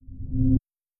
select.wav